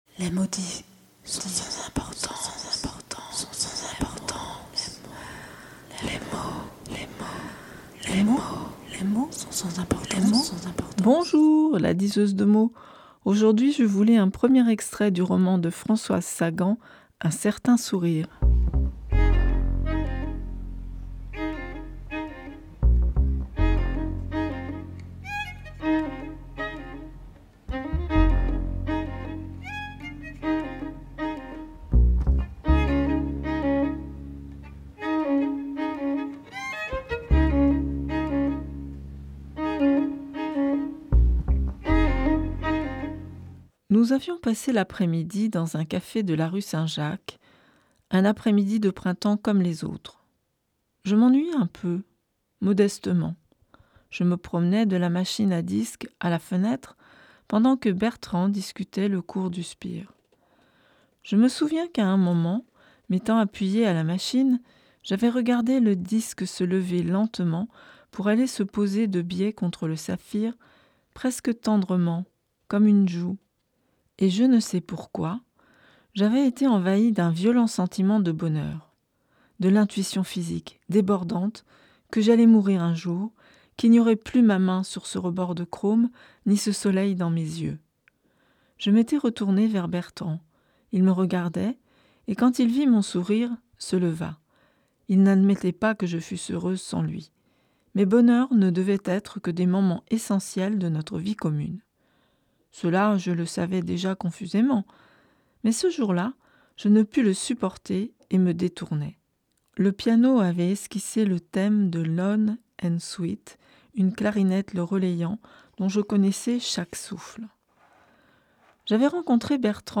1er extrait du livre "Un Certain Sourire" de Françoise Sagan